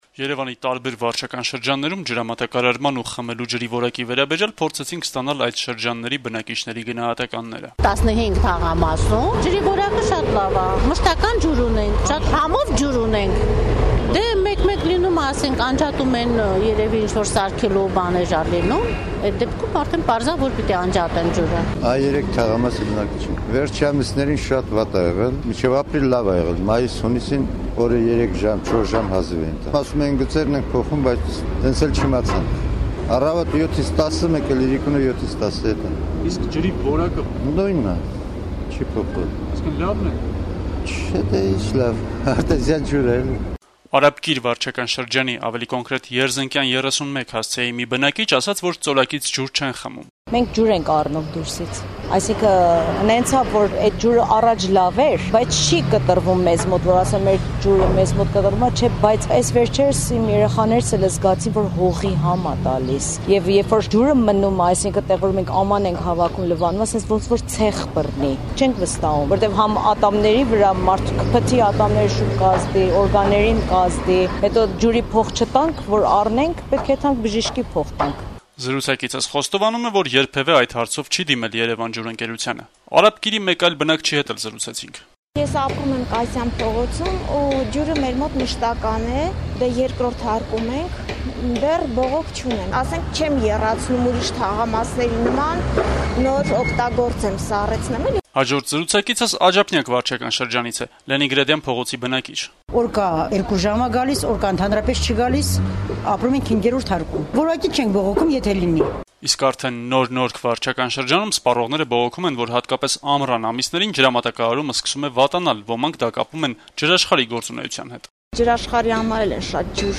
Երեւանում ջրամատակարարման վիճակի եւ մատակարարվող խմելու ջրի որակի մասին «Ազատություն» ռադիոկայանի հետ զրույցներում իրենց գնահատականներ են հնչեցնում մայրաքաղաքի տարբեր վարչական շրջանների բնակիչները: